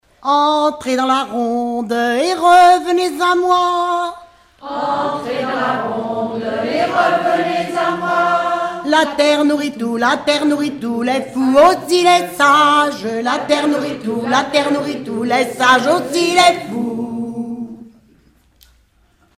Rondes enfantines à baisers ou mariages
Regroupement de chanteurs du canton
Pièce musicale inédite